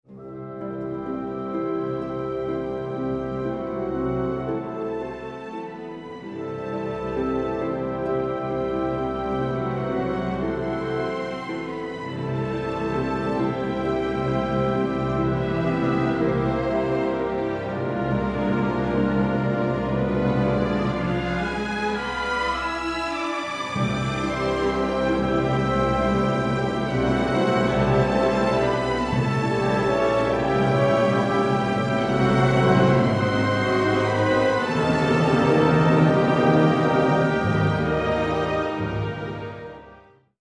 Tenor
The four ‘symphonic poems for voice and orchestra’